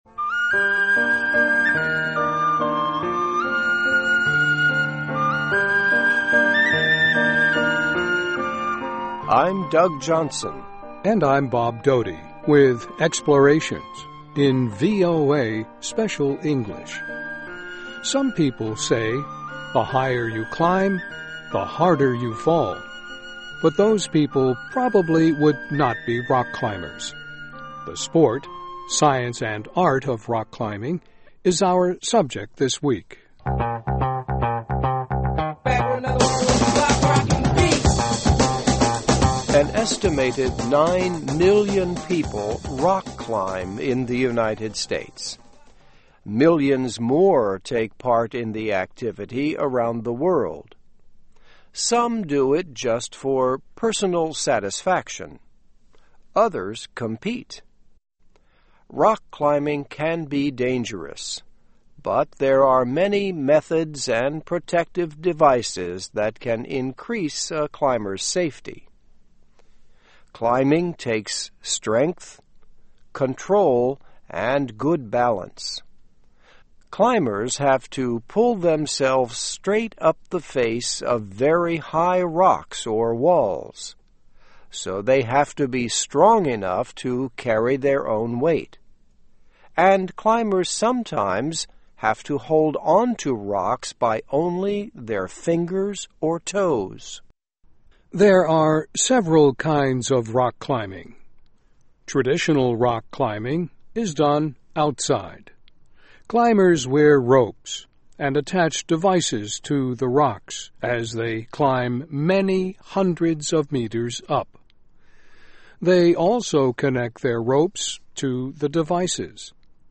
Listen and Read Along - Text with Audio - For ESL Students - For Learning English